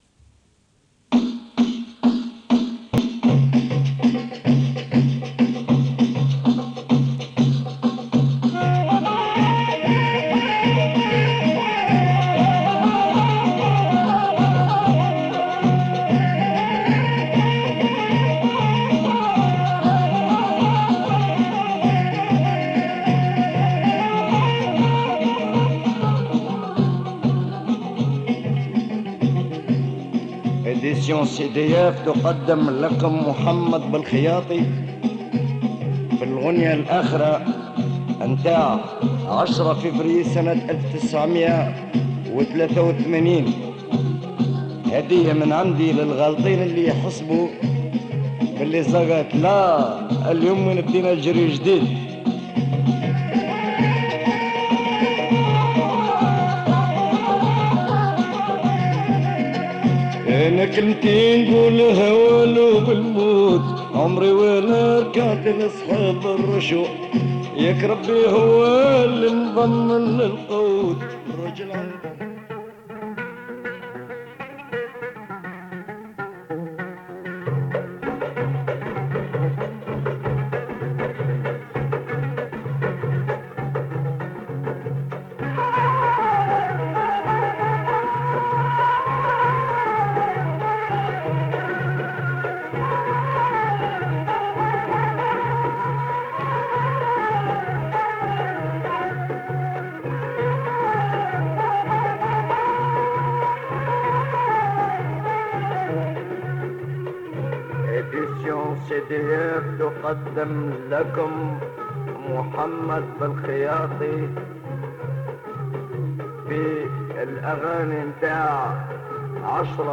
Format : Cassette